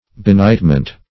Benightment \Be*night"ment\, n. The condition of being benighted.